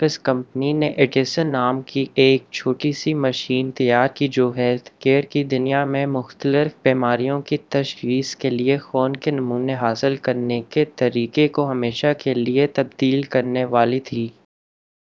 deepfake_detection_dataset_urdu / Spoofed_TTS /Speaker_03 /128.wav